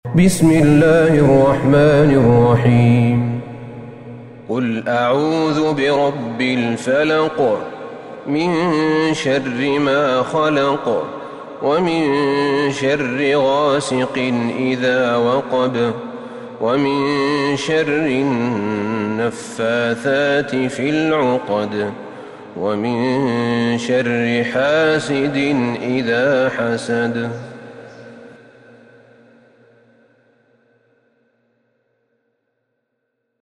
سورة الفلق Surat Al-Falaq > مصحف الشيخ أحمد بن طالب بن حميد من الحرم النبوي > المصحف - تلاوات الحرمين